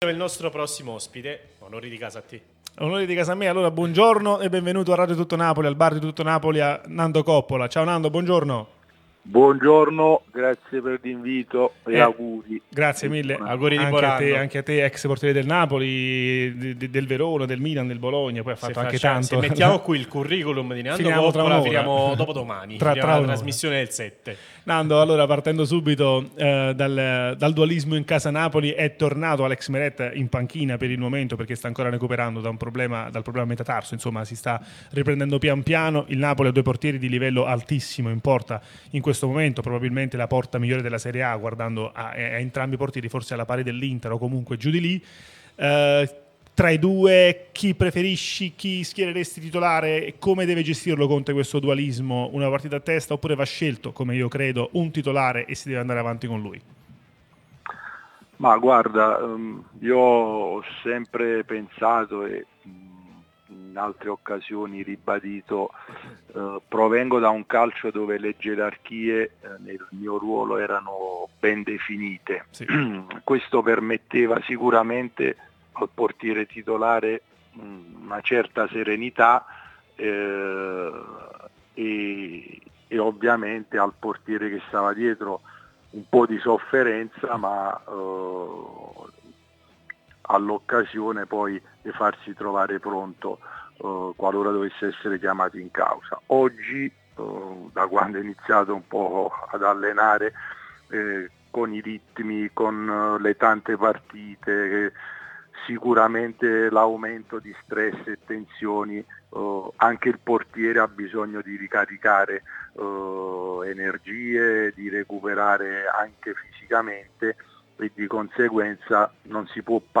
trasmissione sulla nostra Radio Tutto...